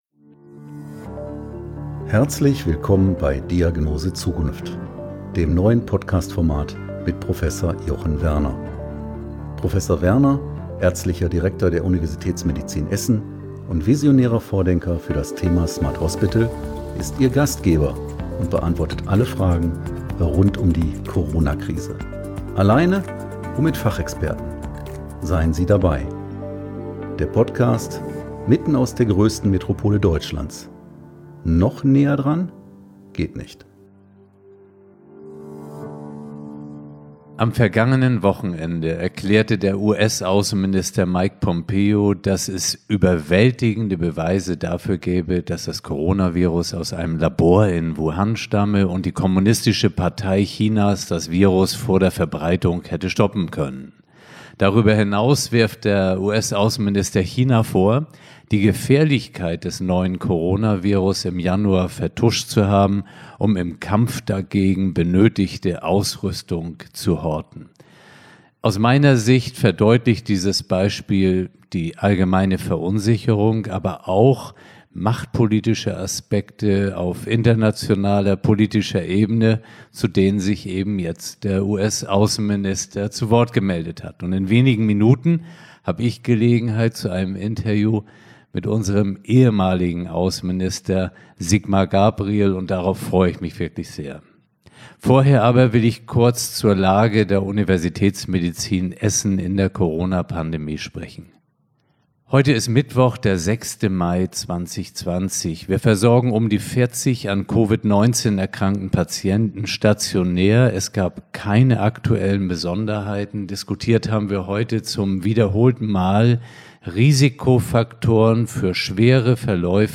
Neben diesen Themen bin ich im zweiten Teil meines Podcasts heute im Gespräch mit Sigmar Gabriel - ehemaliger Politiker und Vizekanzler. Der Titel dieser Podcast-Folge ist ein O-Ton von Sigmar Gabriel, den er als Resume seiner bisherigen Reflektion über das Thema zieht: Das Virus ist gnadenlos.